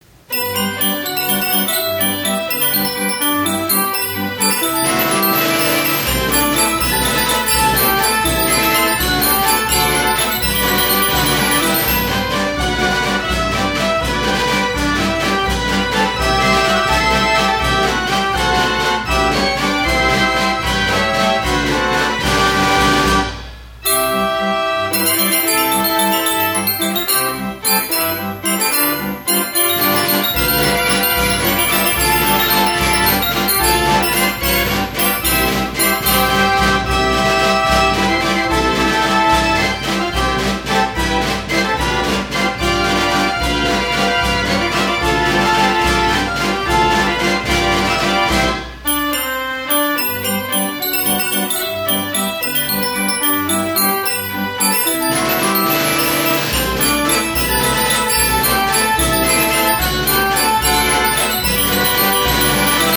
It has 54 keys and plays from paper rolls of music.
Traps: bass drum; snare drum and cymbal.